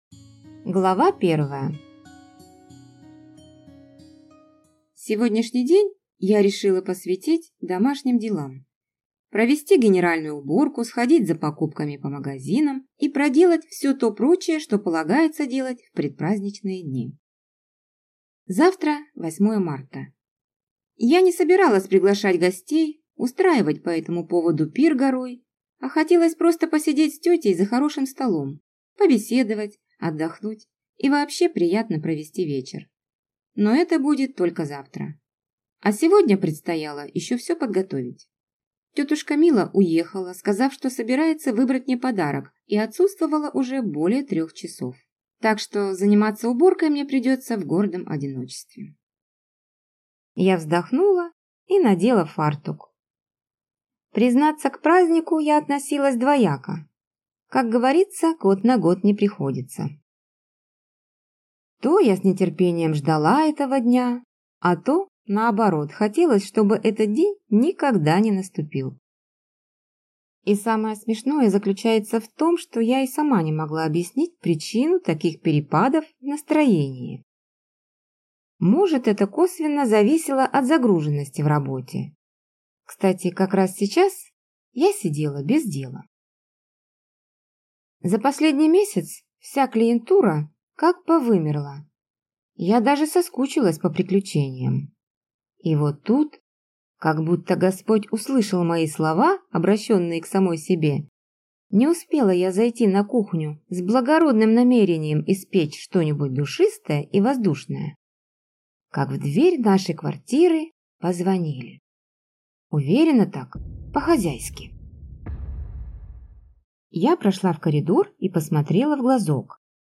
Аудиокнига Смех сквозь слезы | Библиотека аудиокниг